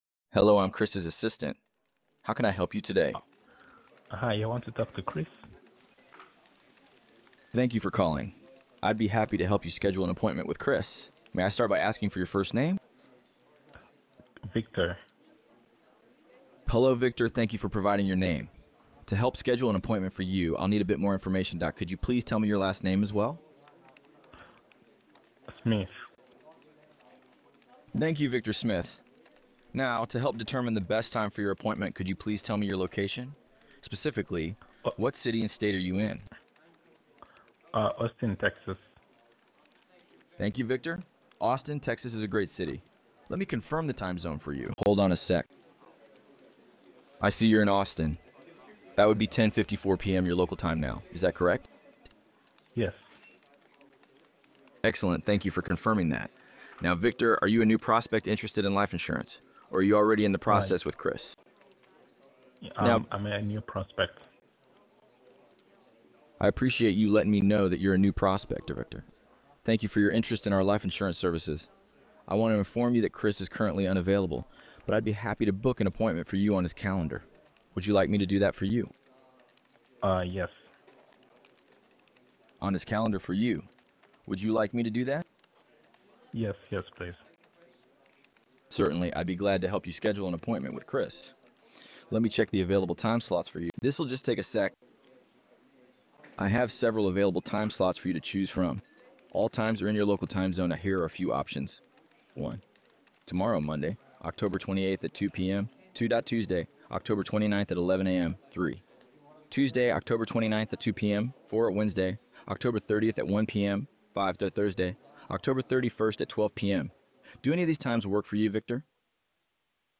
• Natural, human-like conversations
Instantly handle all inbound calls with a natural, human-like voice to capture every single lead.